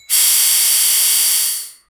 I want to loop the sound, so the ending of the sound should seamlessly attach to start of the sound. 0:10 steam pipe rupture and leak 0:10 Vacuum bell releasing steam 0:02
vacuum-bell-releasing-ste-h4czc7et.wav